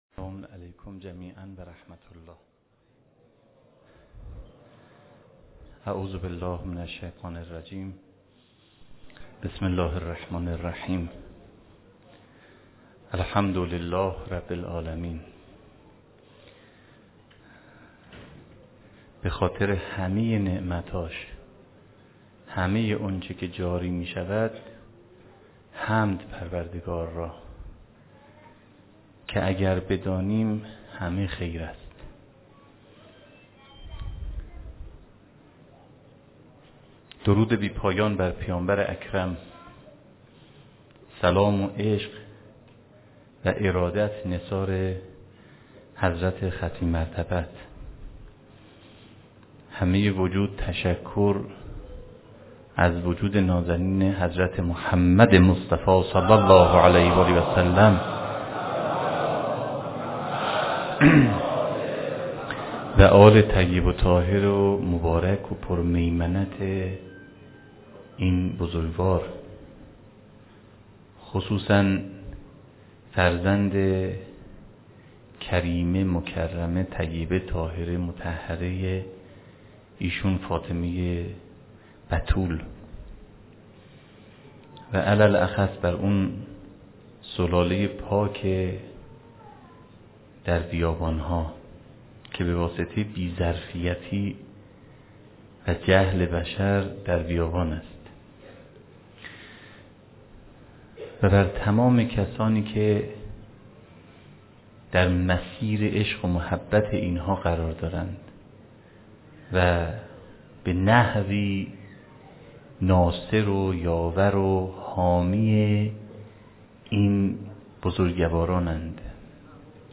سخنرانی
فاطمیه 93 برازجان